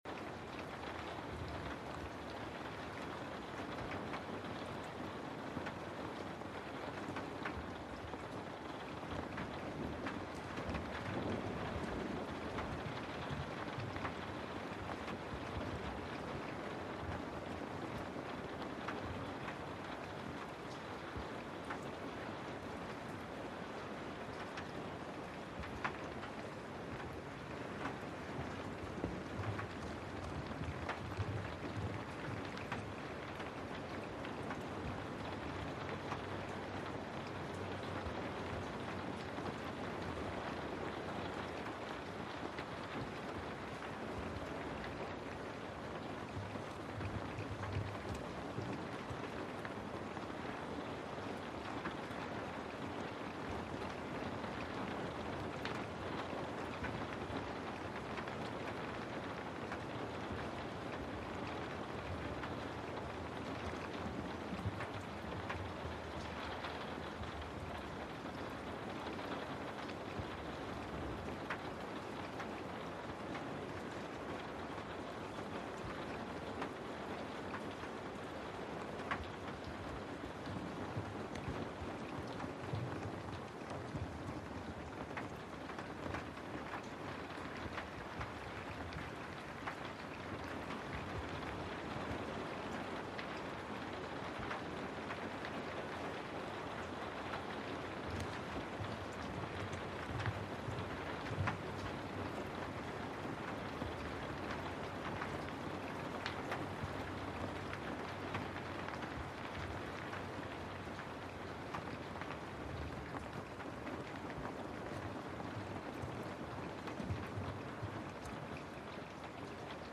moodist - 🌲 Ambient sounds for focus and calm.
rain-on-window.mp3